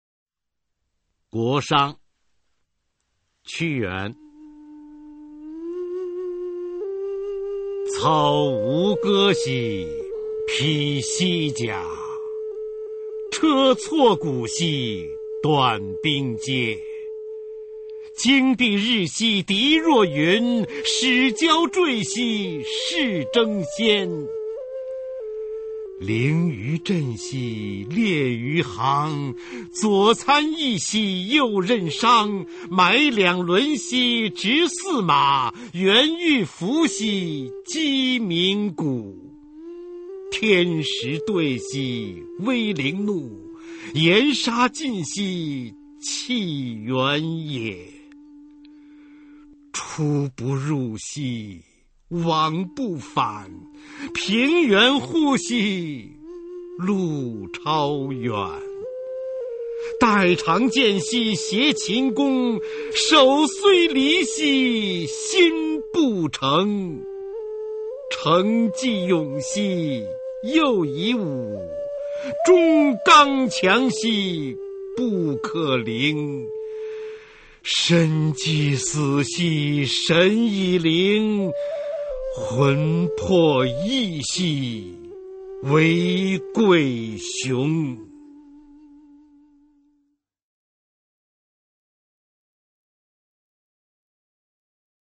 [先秦诗词诵读]屈原-九歌-国殇 朗诵